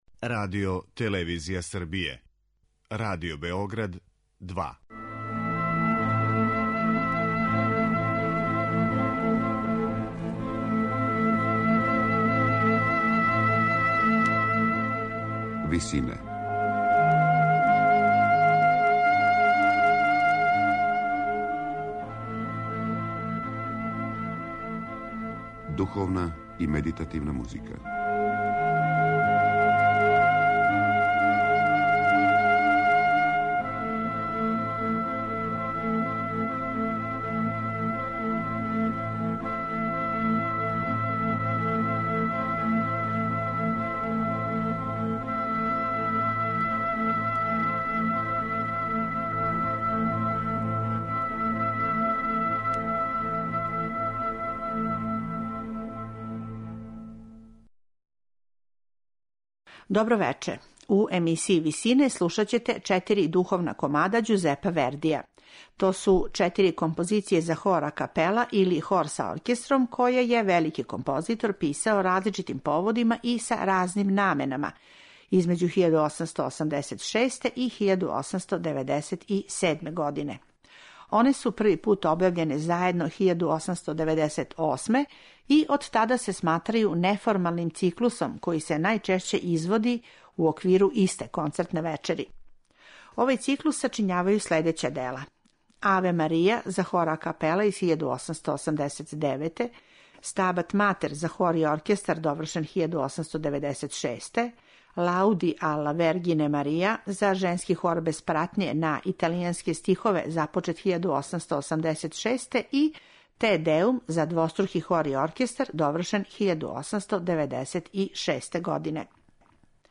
На крају програма, у ВИСИНАМА представљамо медитативне и духовне композиције аутора свих конфесија и епоха.
Слушате их са концертног снимка из фебруара 2012. године, када су их извели Хор и СО РТС-а